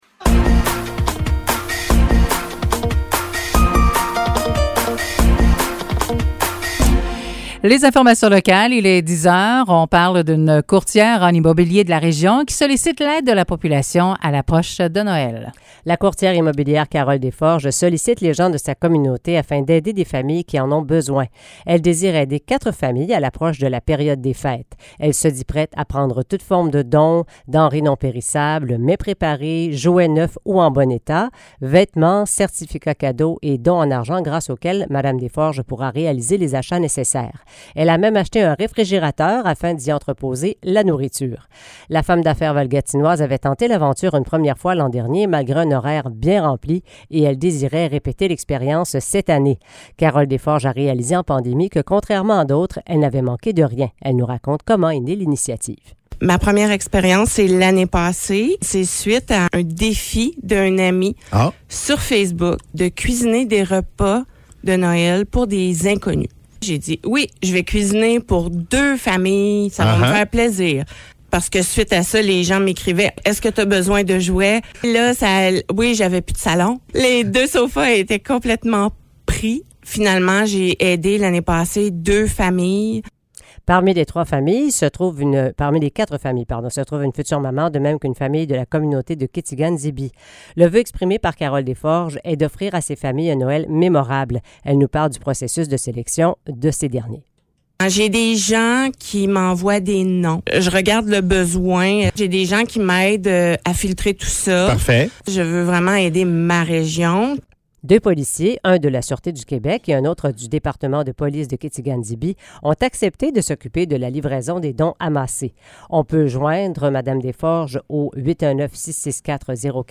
Nouvelles locales - 6 décembre 2022 - 10 h